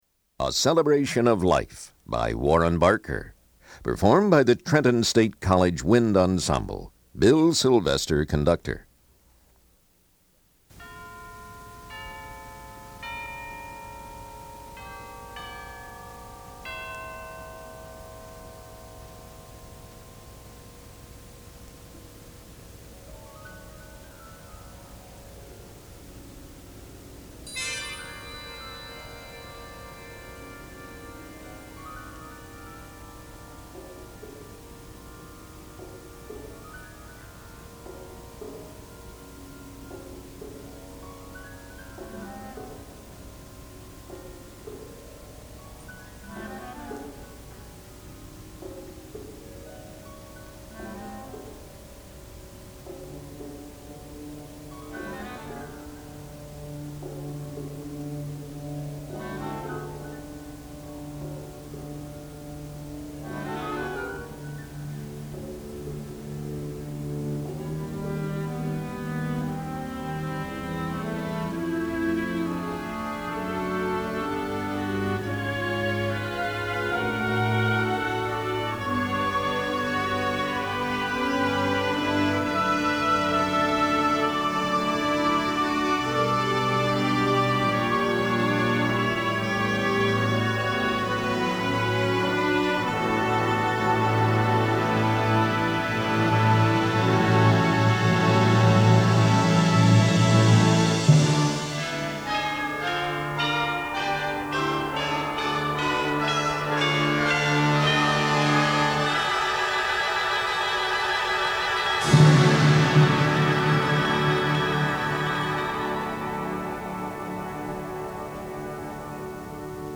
輸入吹奏楽オリジナル作品